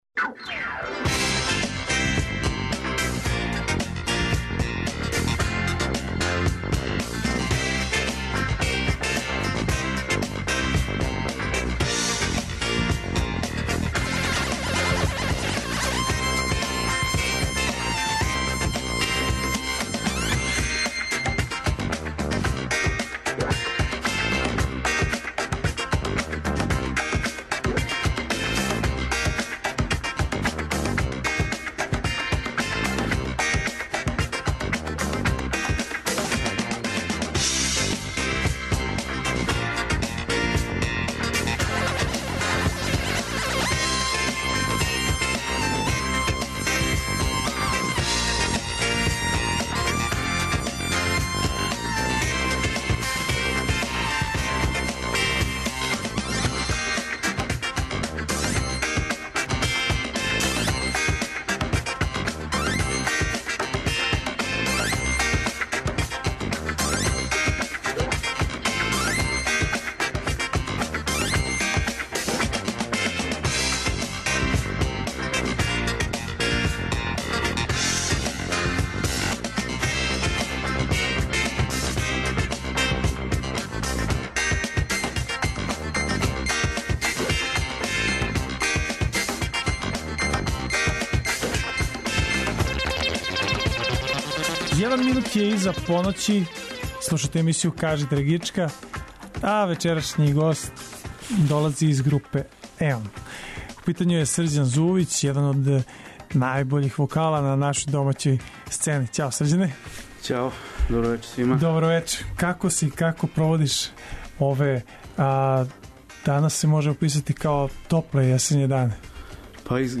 У госте нам долази београдска група ’’Еон’’, ауторски бенд који на наступима изводи и обраде различитих поп-рок хитова у сопственим аранжманима.